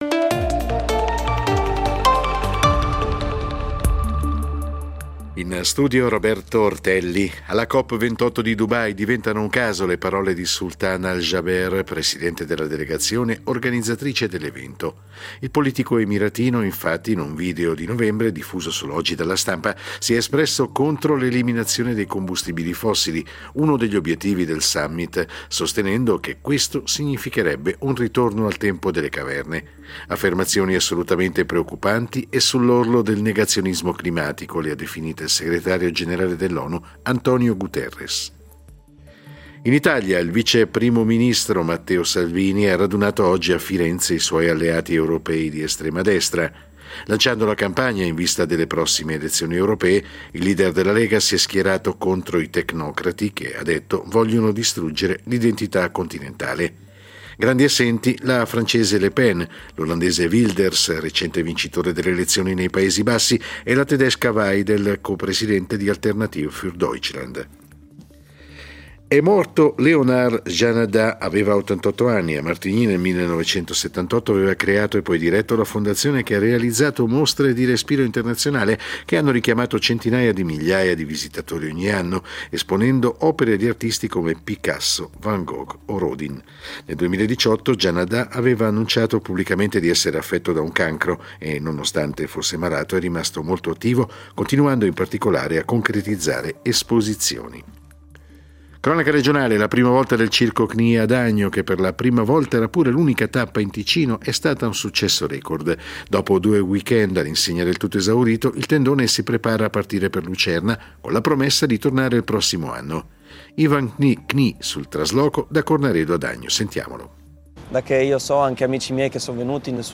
Notiziario delle 20:00 del 03.12.2023